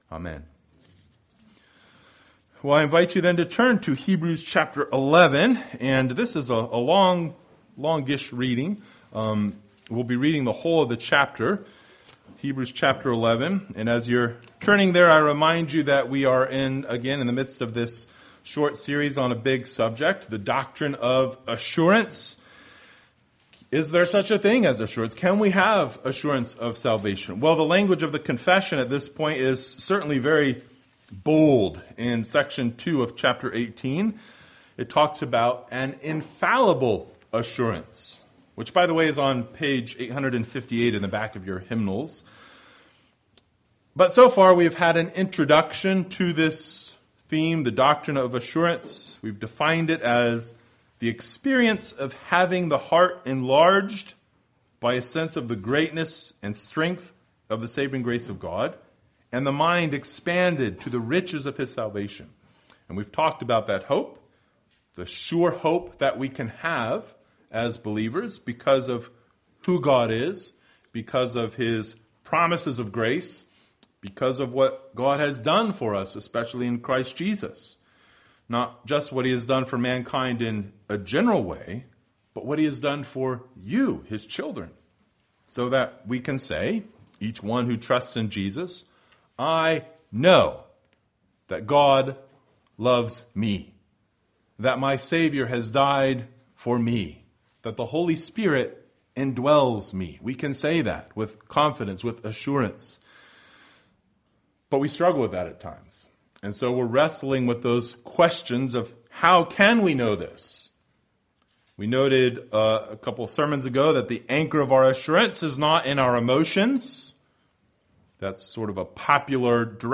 Hebrews 11:1-40 Service Type: Sunday Morning Bible Text